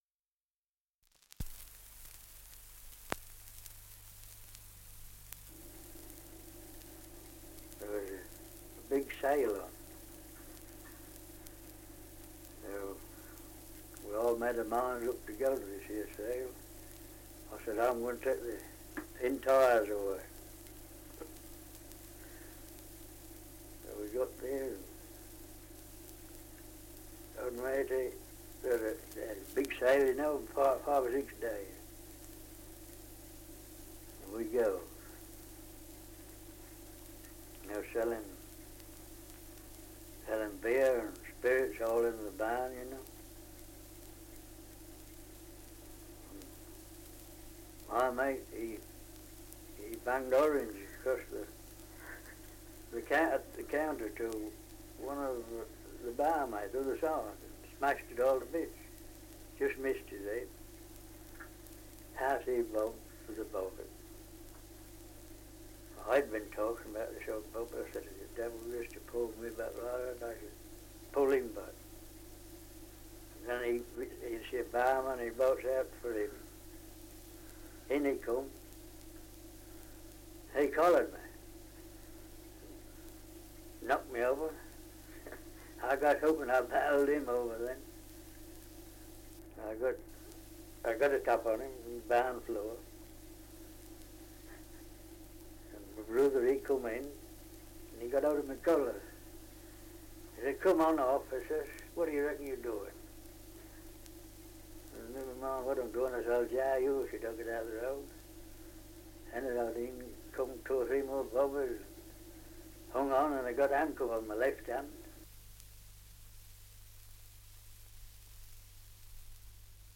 Survey of English Dialects recording in Sutterton, Lincolnshire
78 r.p.m., cellulose nitrate on aluminium